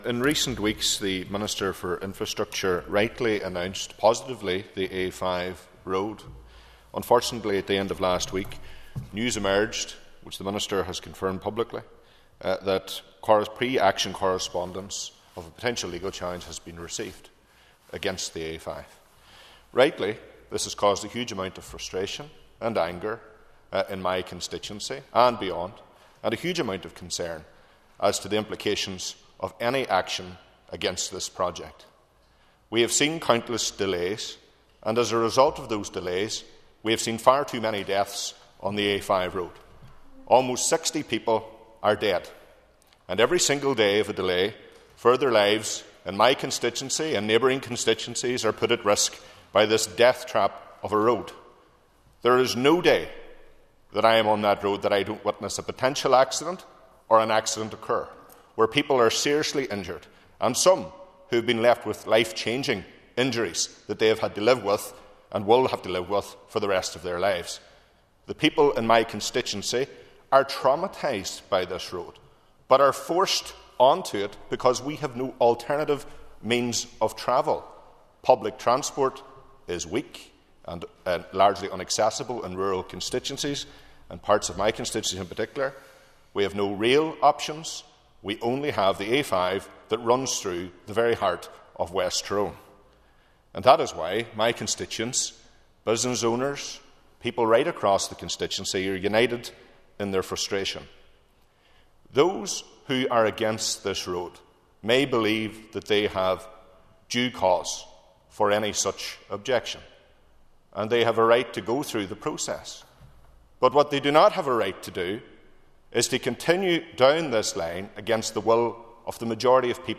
Speaking at Stormont, West Tyrone MLA Daniel McCrossan urged the objectors to withdraw their legal challenge:
daniel-mccrossan-web.mp3